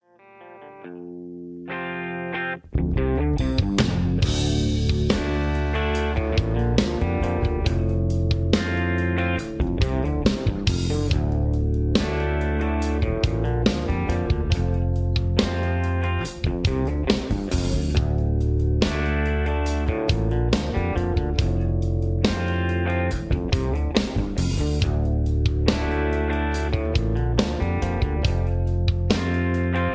karaoke, backingtracks